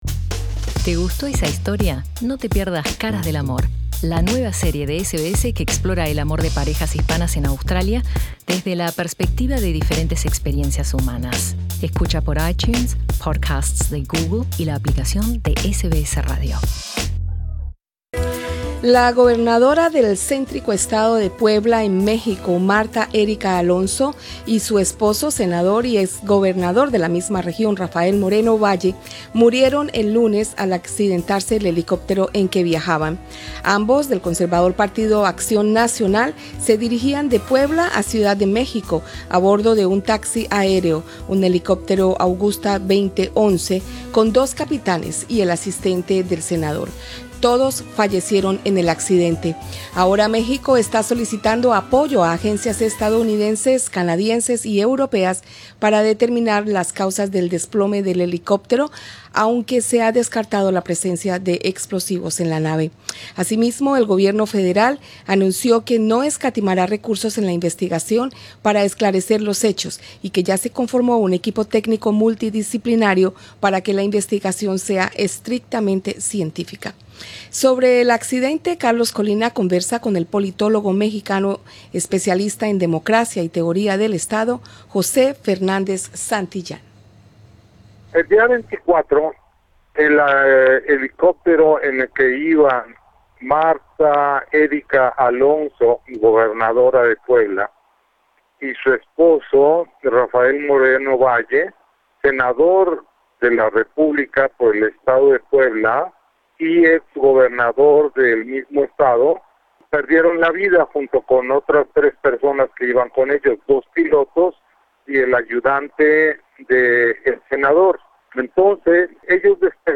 Sobre el accidente, entrevista con el politólogo mexicano especialista en democracia y teoría del estado